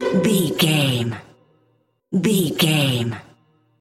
Lydian
strings
orchestra
percussion
silly
circus
goofy
comical
cheerful
perky
Light hearted
quirky